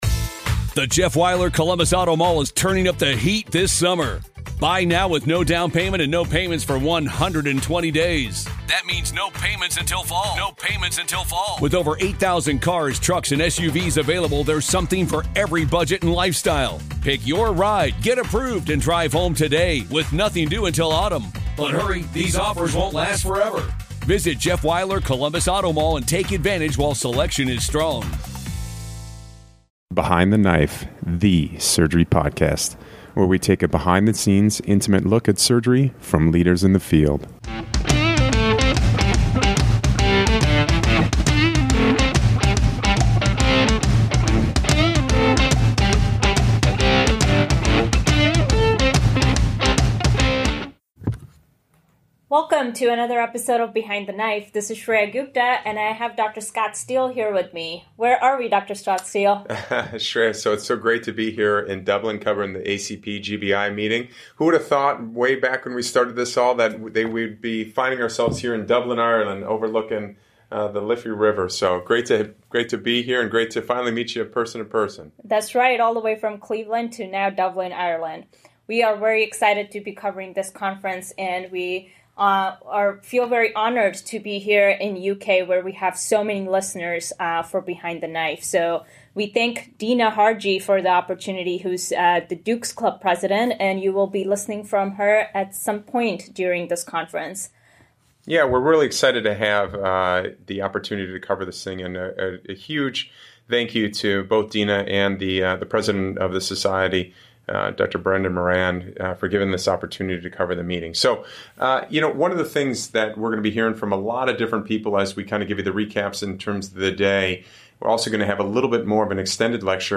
Association of Coloproctology of Great Britain and Ireland Annual Conference 2019 @ Dublin, Ireland.